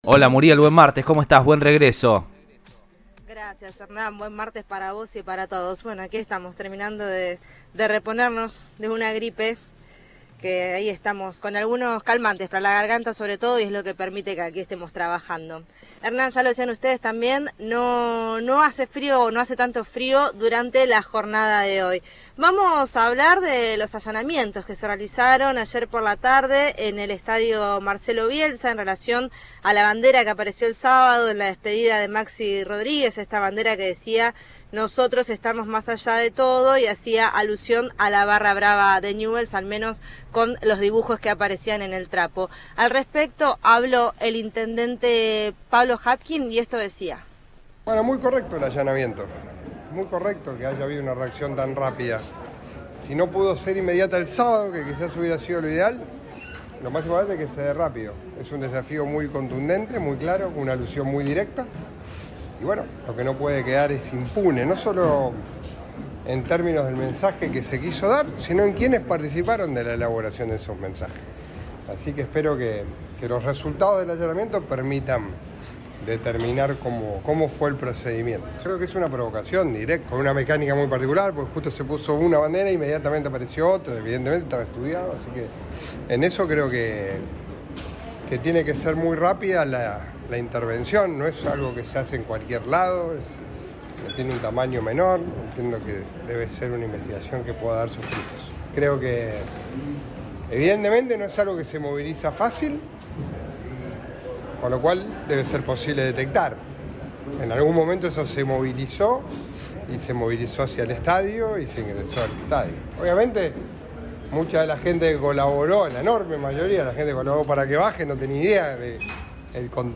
“Es muy correcto el allanamiento y que haya habido una reacción tan rápida. Es un desafío muy claro, una alusión muy directa. No puede quedar impune. No solo en torno al mensaje que se dio, sino en relación a quienes participaron en eso”, indicó en contacto con el móvil de Cadena 3 Rosario, en Radioinforme 3.